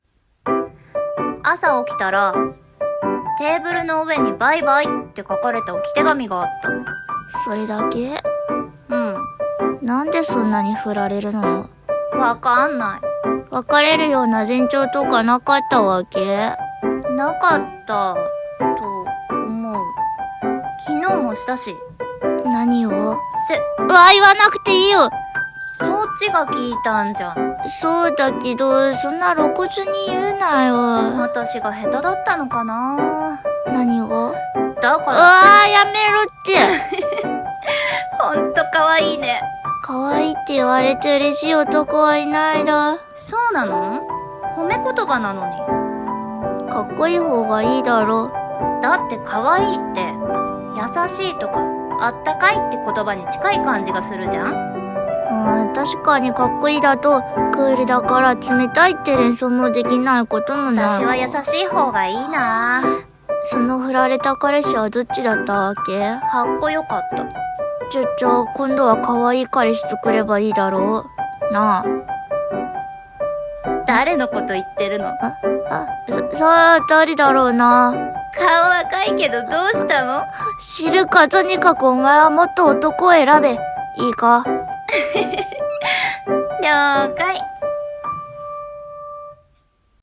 【二人台声劇本】『せっ』